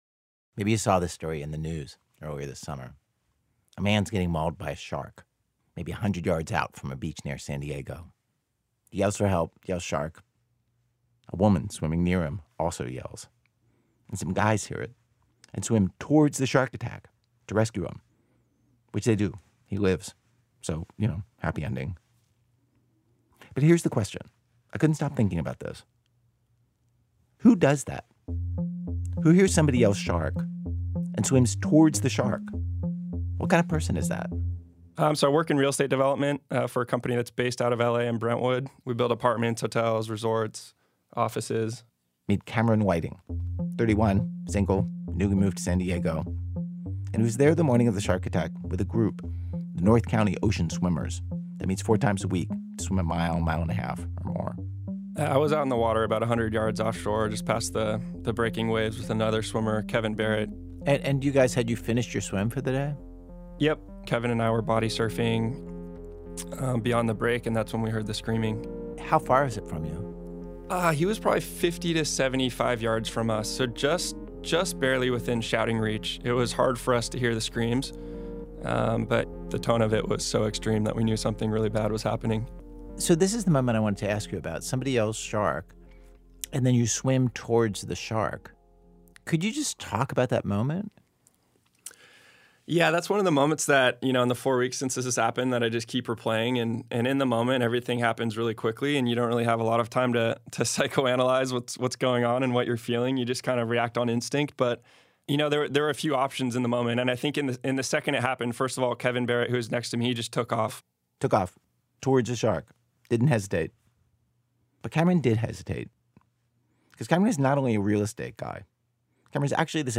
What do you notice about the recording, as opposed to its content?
Note: The internet version of this episode contains un-beeped curse words.